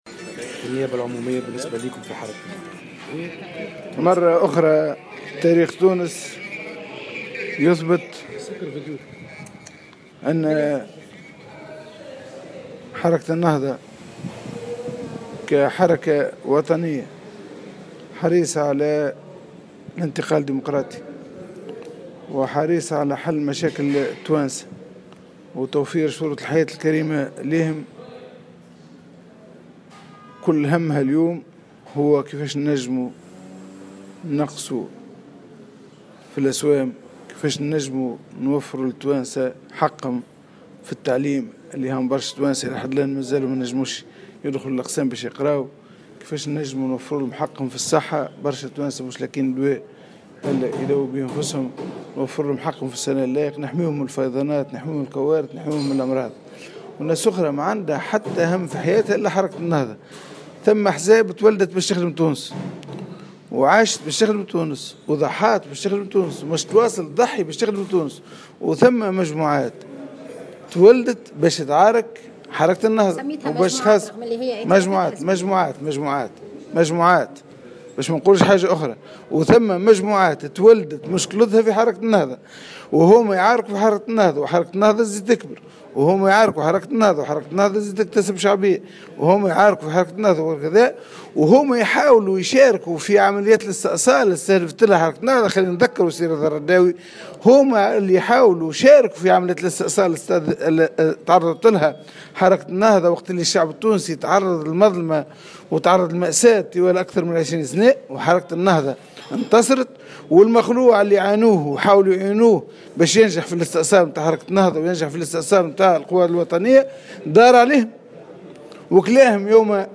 قال القيادي في حركة النهضة و وزير عدل حكومة الترويكا نور الدين البحيري في تصريح لمراسل الجوهرة "اف ام" اليوم الاثنين إن هناك أحزاب ولدت لخدمة تونس وعاشت وضحت في سبيلها على غرار حركة النهضة ومجموعات ولدت لتحارب النهضة وليس لها مشكلة إلا معها.